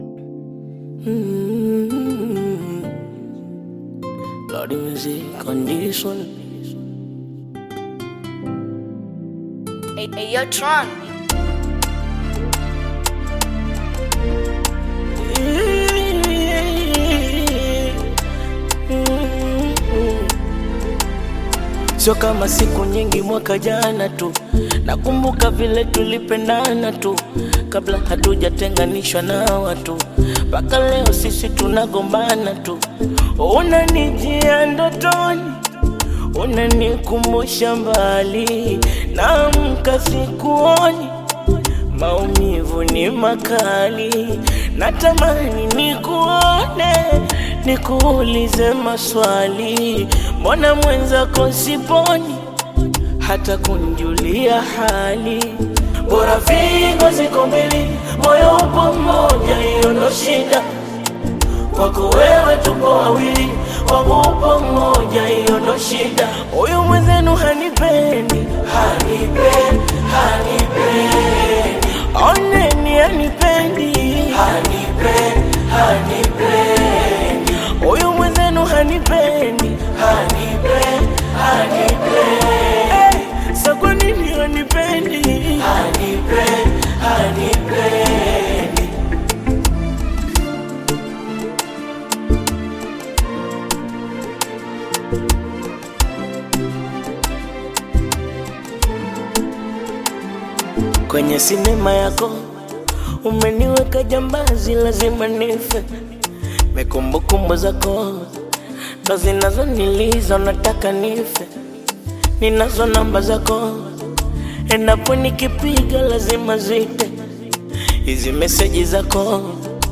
Bongo Flava
This catchy new song